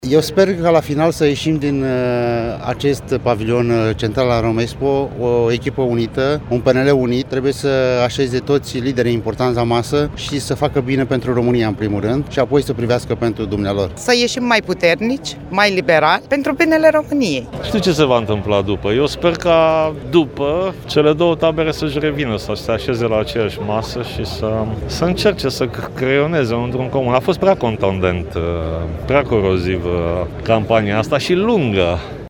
Sâmbătă, la Romexpo, se desfășoară congresul Partidului Național Liberal.
“Să ieșim mai puternici, mai liberali pentru binele României” spune o altă delegată liberală.